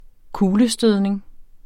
Udtale [ -ˌsdøðˀneŋ ]